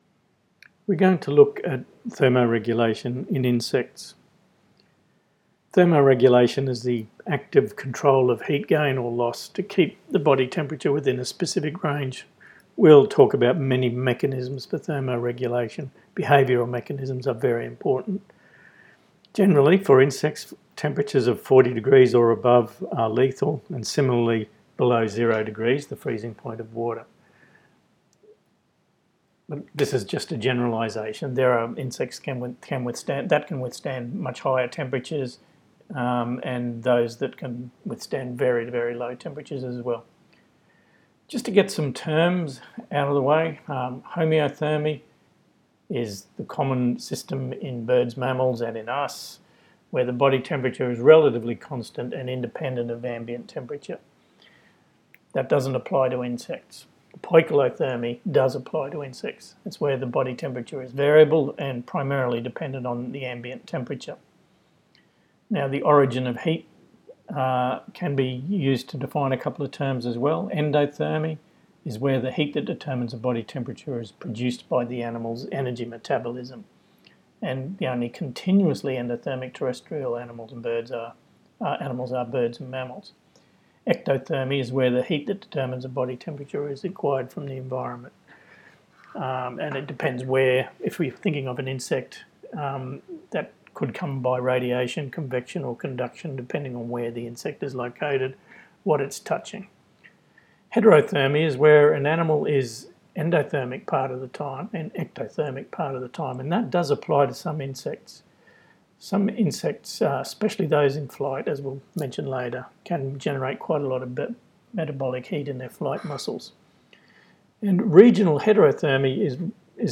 Mini-lecture:
thermoregulation2018audio.m4a